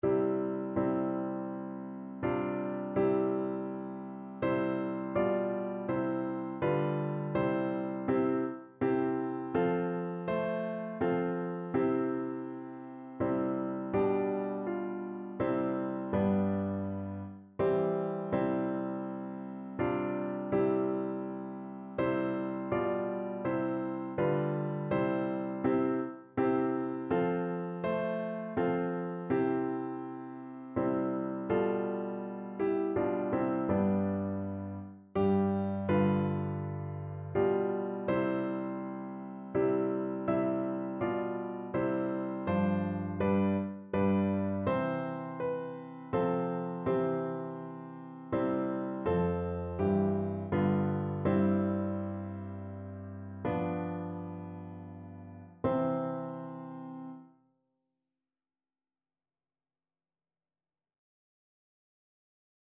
Notensatz 1 (4 Stimmen gemischt)
• gemischter Chor mit Akk. [MP3] 979 KB Download